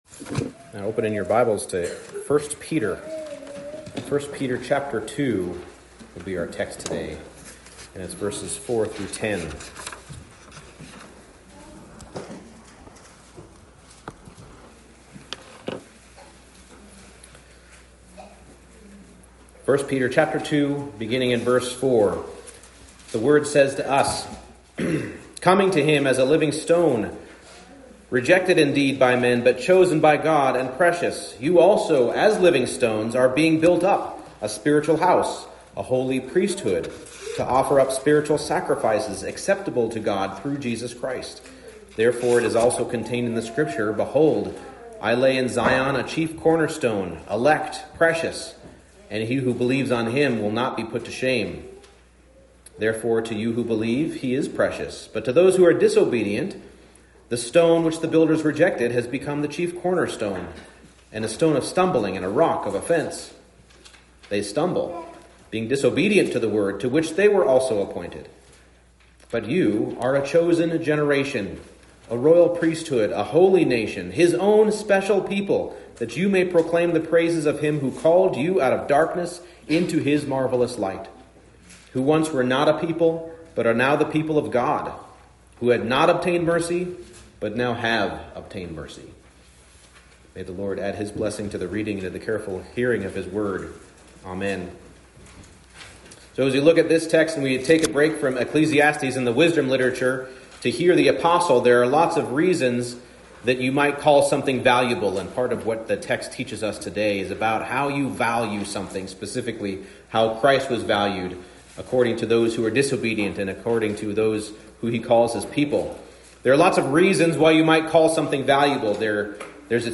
1 Peter 2:4-10 Service Type: Morning Service You who believe were chosen in Christ to be the people of God.